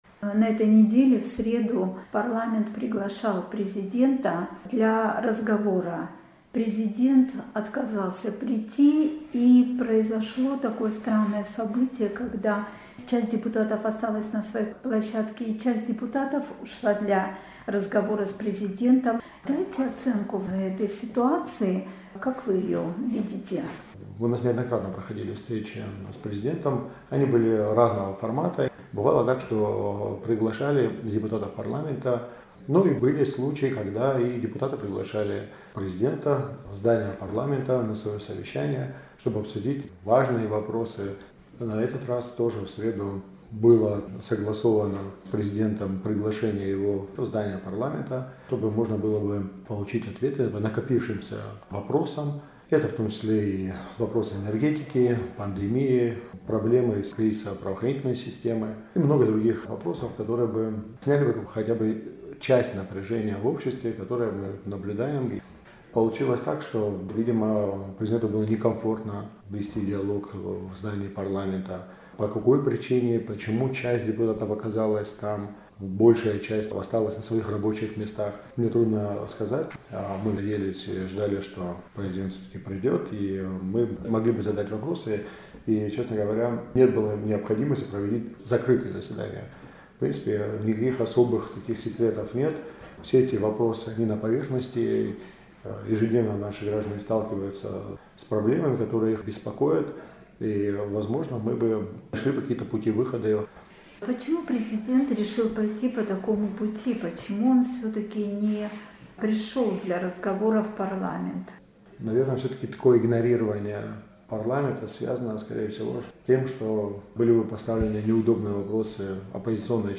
Председатель комитета по государственно-правовой политике парламента Абхазии Валерий Агрба в интервью «Эху Кавказа» дал оценку актуальным событиям в республике.